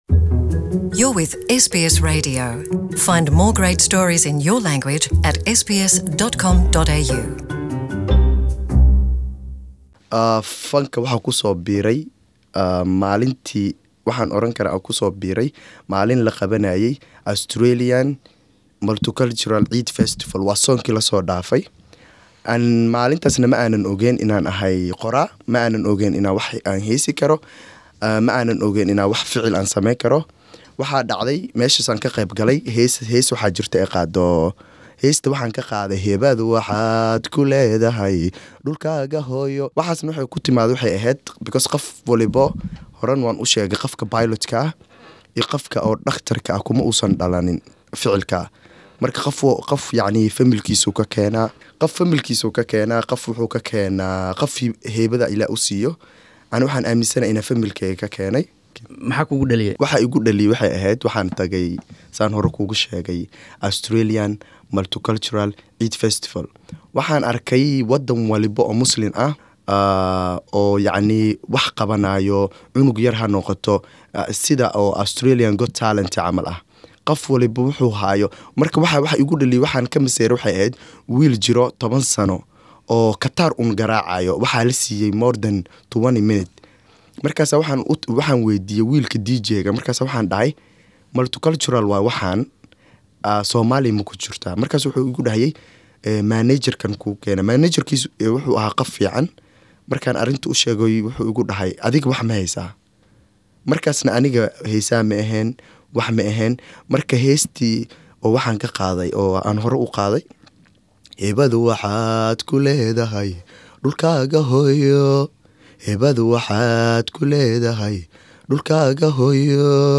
Waraysi
Interview